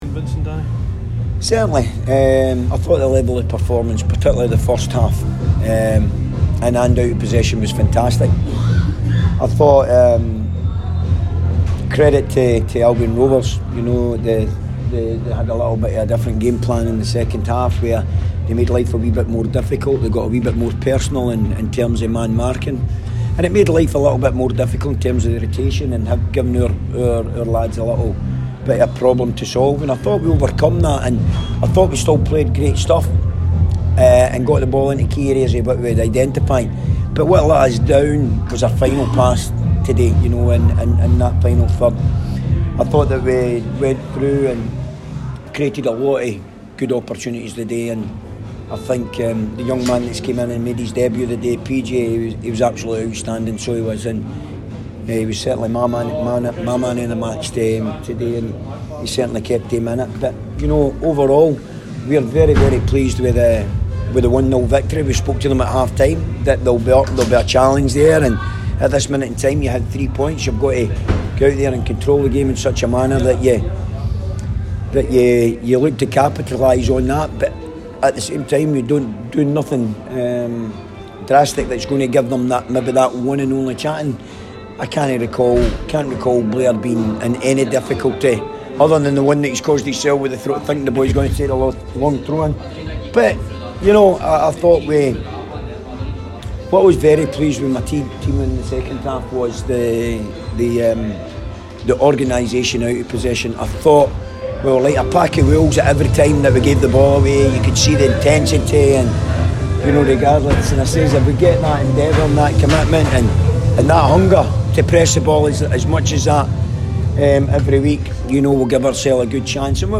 press conference after the Ladbrokes League 2 match.